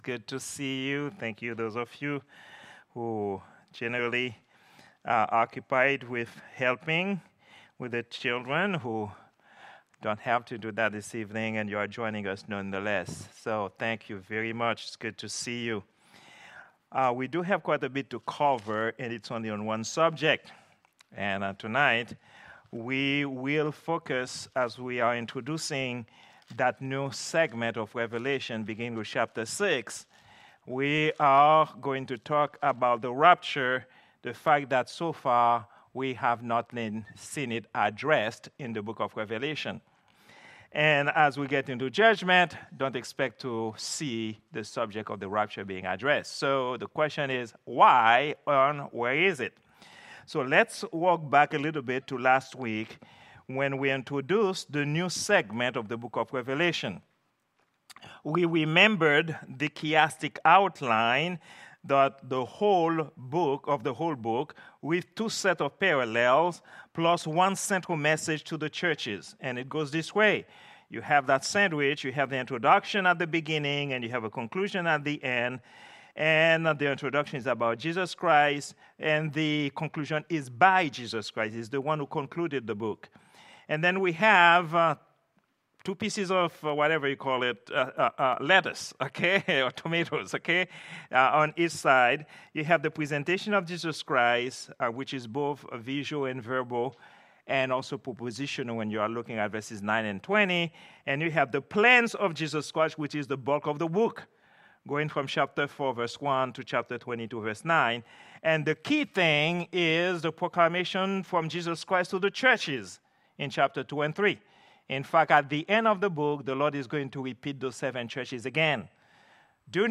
We are a baptist church located in Grand Haven, MI.
Prayer_Meeting_05_01_2024.mp3